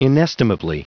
Prononciation du mot inestimably en anglais (fichier audio)
Prononciation du mot : inestimably